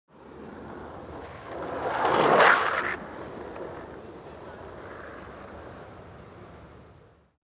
جلوه های صوتی
دانلود صدای اسکیت 2 از ساعد نیوز با لینک مستقیم و کیفیت بالا